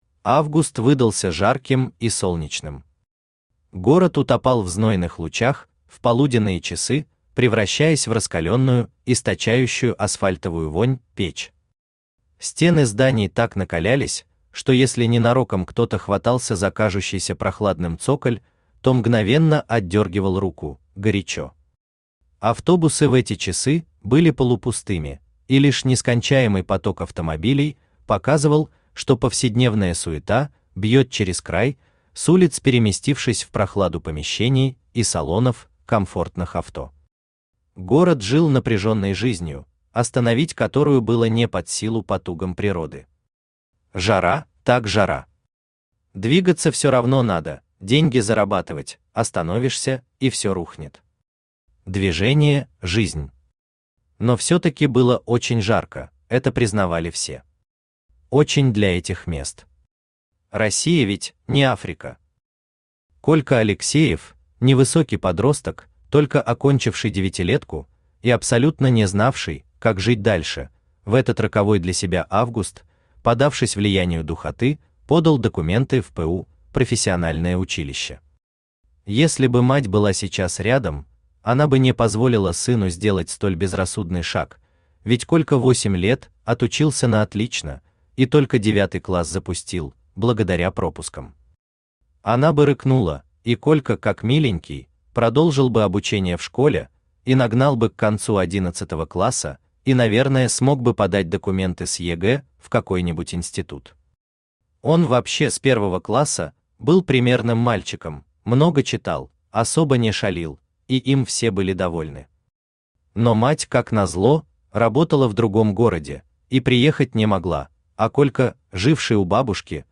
Aудиокнига Белым по белому Автор Алексей Николаевич Наст Читает аудиокнигу Авточтец ЛитРес.